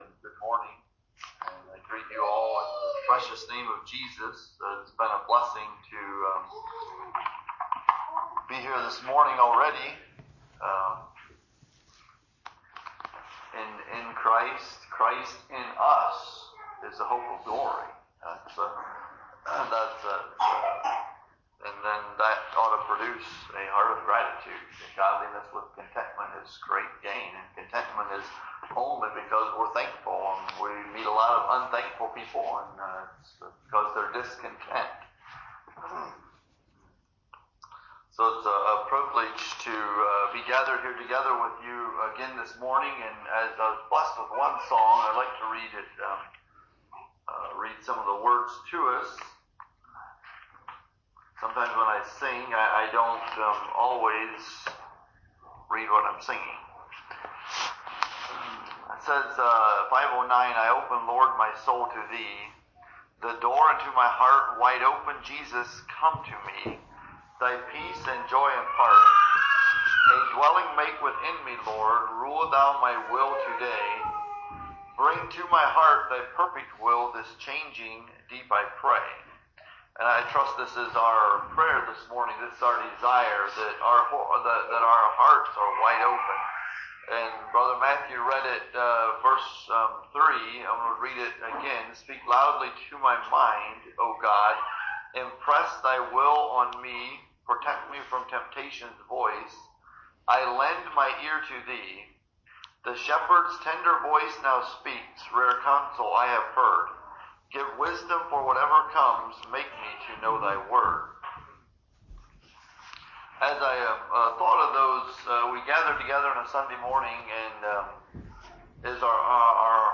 A message from the series "2026 Messages."